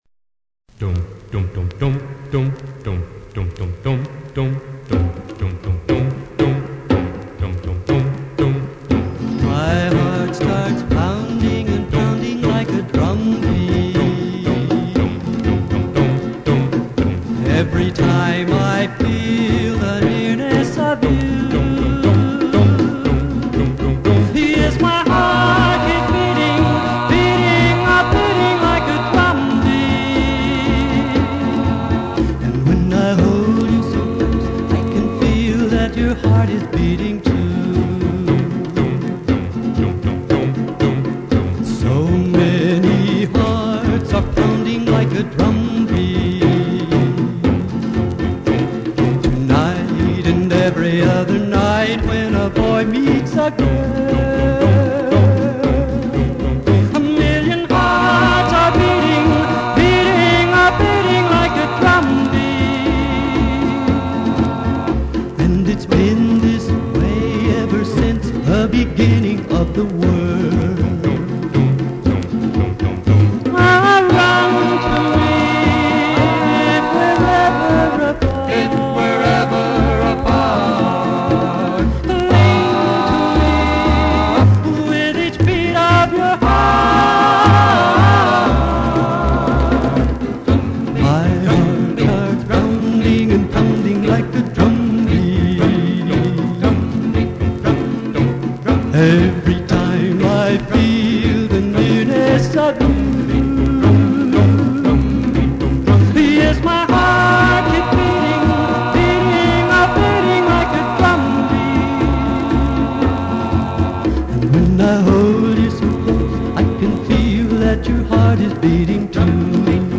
professionally done on 45rpm records